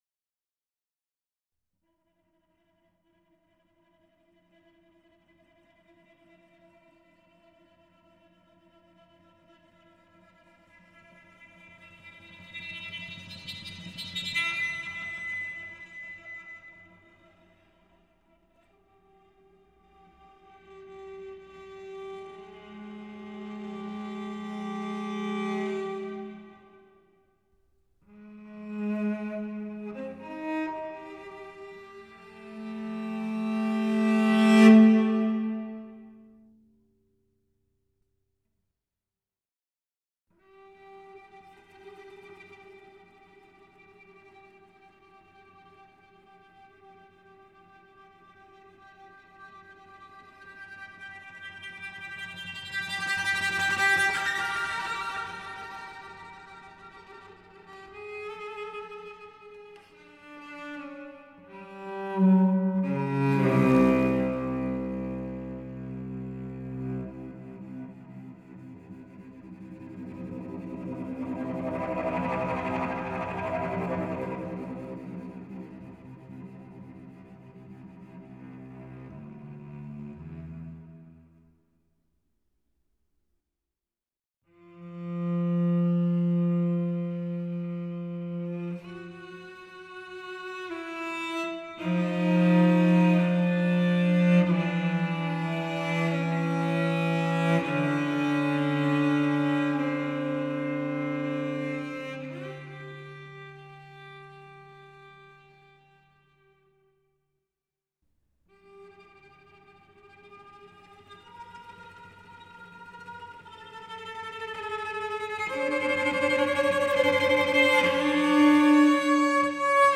fantasia on jingle bells (2025 Christmas Music Event Submission) - Chamber Music - Young Composers Music Forum
I've basically decided to get really good at writing for strings nowadays, and since I'm mostly an atonalist, cello is the easiest since ...